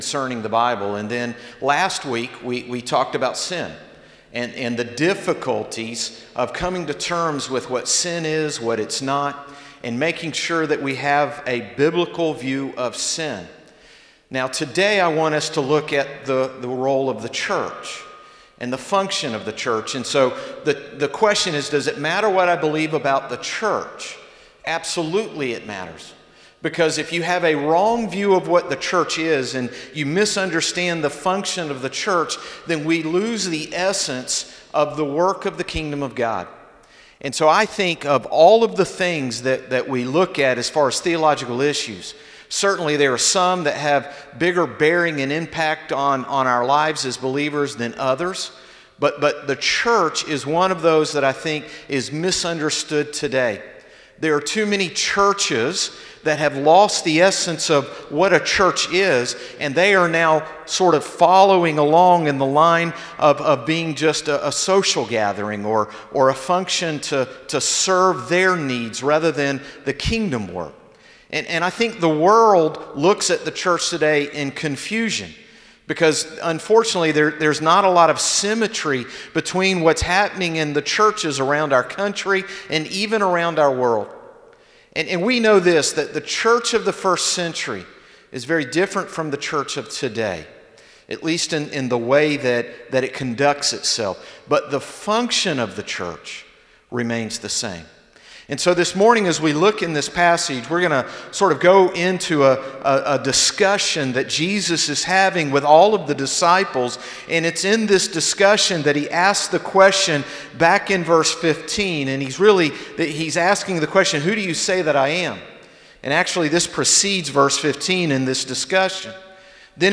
Sermons - Concord Baptist Church
Morning-Service-7-19-20.mp3